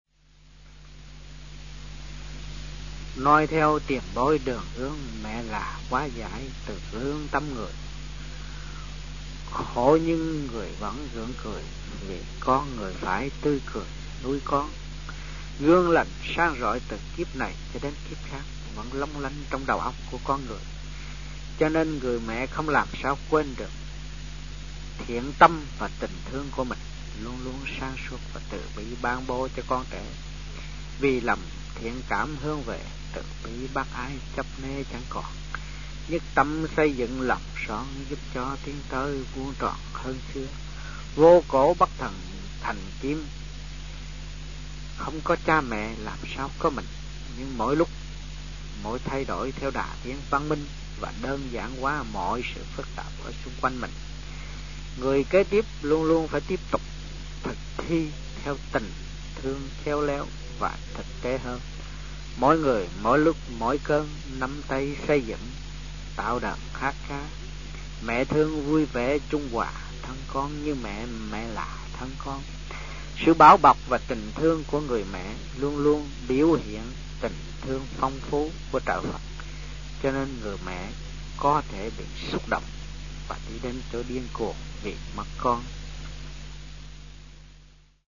Địa danh : Sài Gòn, Việt Nam
Trong dịp : Sinh hoạt thiền đường